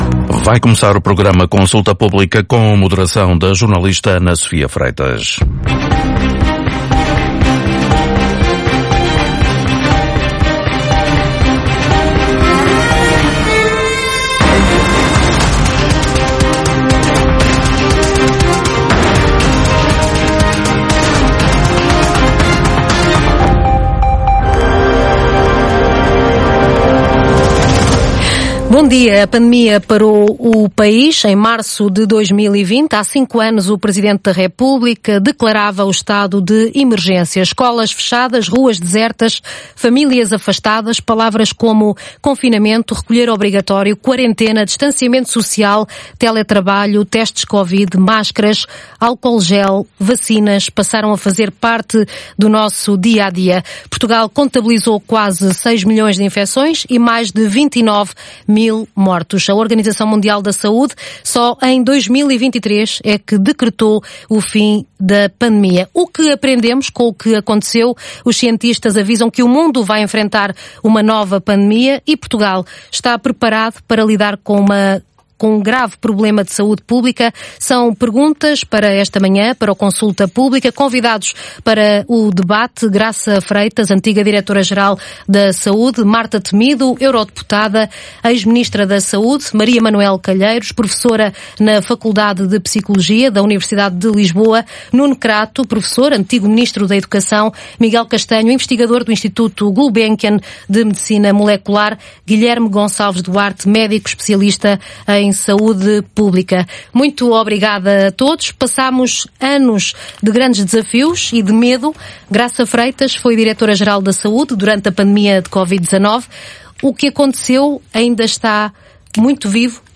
Entrevista na Antena 1: Pandemia – O que aprendemos com o que aconteceu?